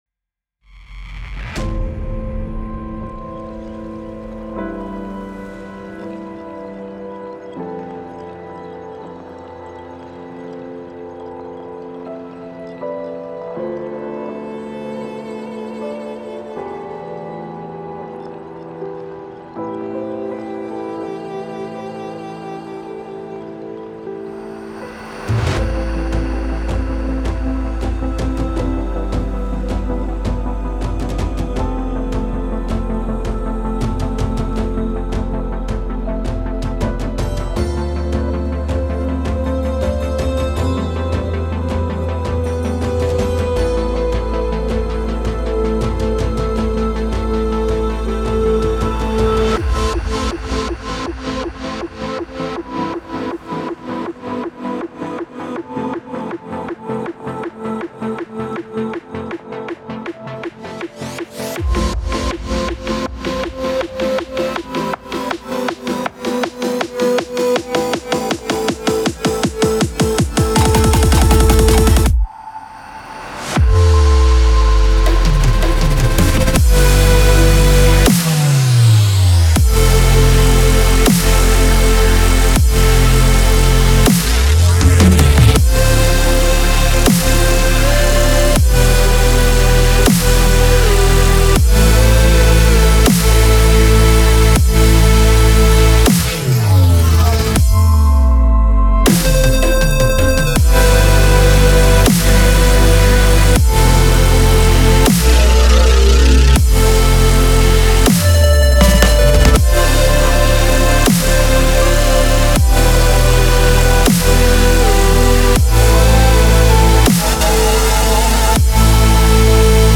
это трек в жанре альтернативного попа